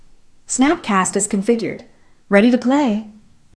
snapcast-is-configured-ready2play.wav